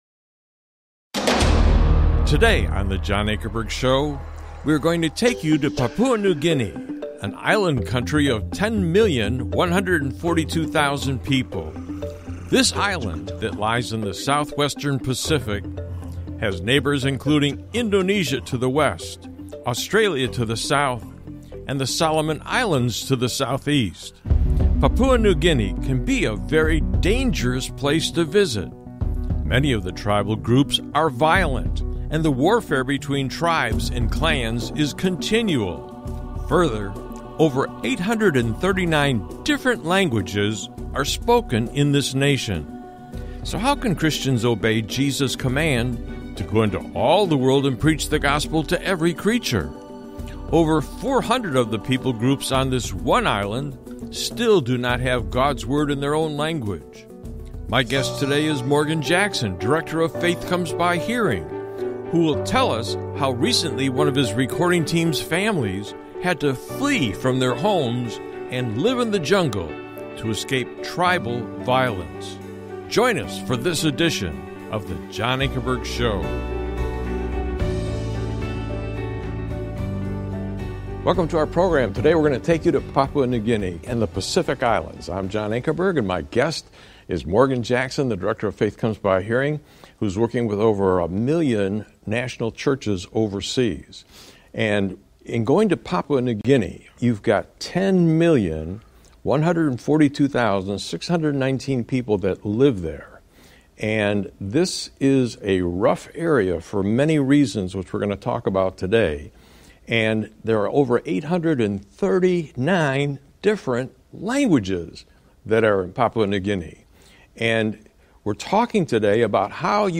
The formats of choice for this apologetics ministry are informal debates between representatives of differing belief systems, and documentary-styled presentations on major issues in society to which the historic Christian faith has something of consequence to say.